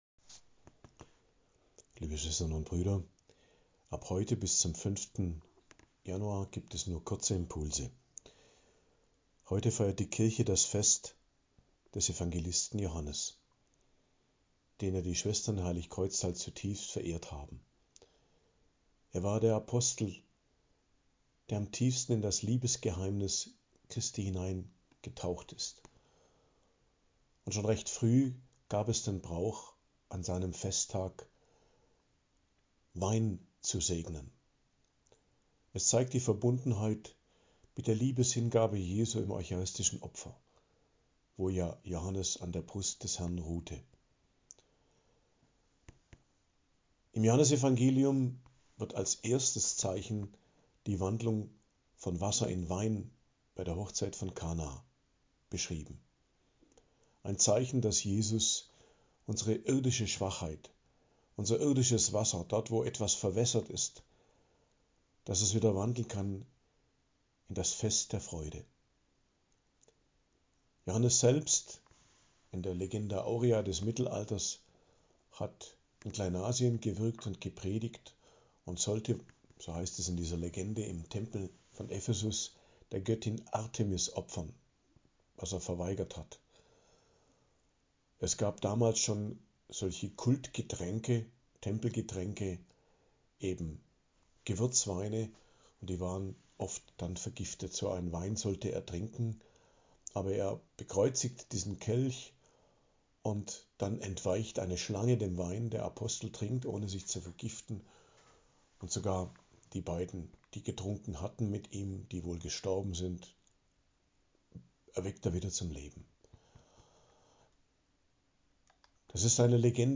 Predigt am Fest des Hl Johannes, Apostel und Evangelist, 27.12.2024